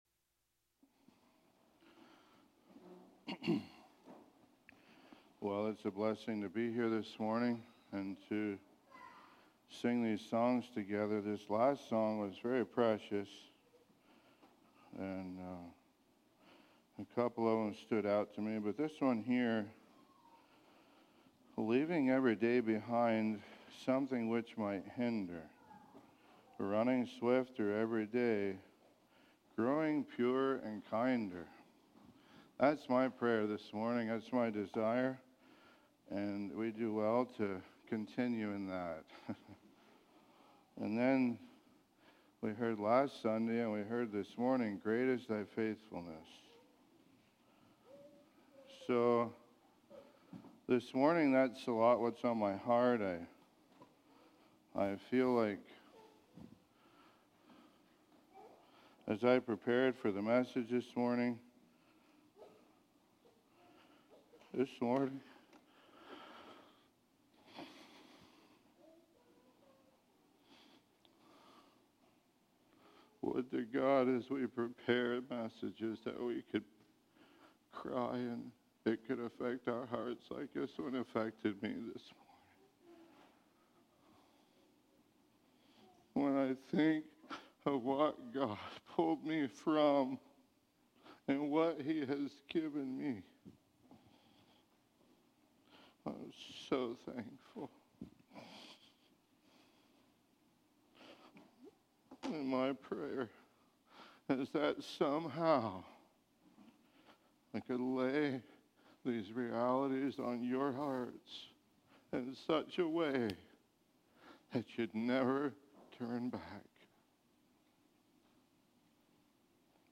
Personal Testimony